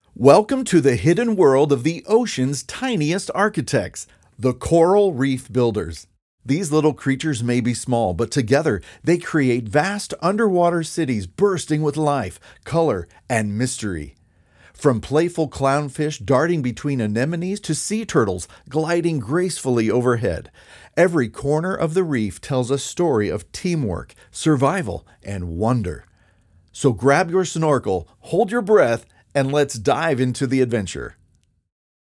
Documentary Fun - Demo
North American English, British (general)
- Professional recording studio and analog-modeling gear